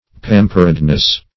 -- Pam"pered*ness , n. --Bp.
pamperedness.mp3